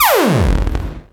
VEC3 Scratching FX